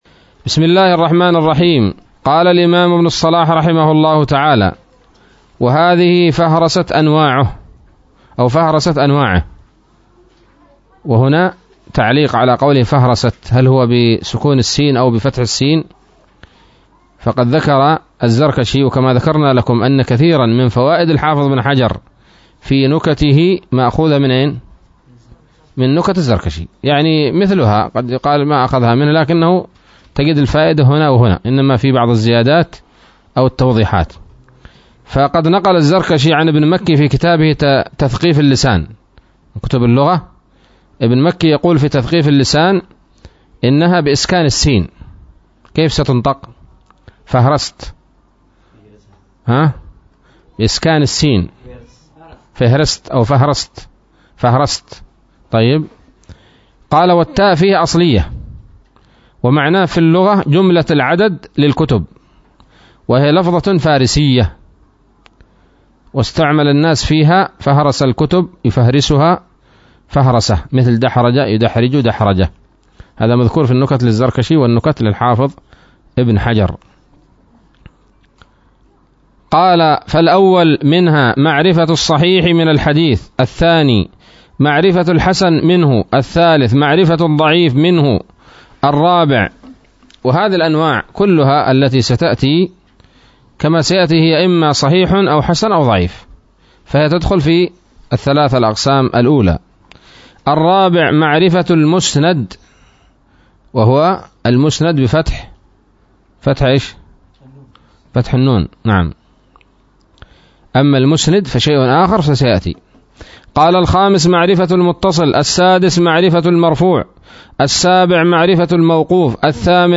الدرس الثالث من مقدمة ابن الصلاح رحمه الله تعالى